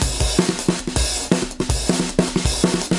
Drumloops " dnb drumloop b 2bars 160bpm
Tag: 低音 沟槽 节奏 碎拍 鼓-loop drumloop 断线 N 160bpm 节拍 DNB drumgroove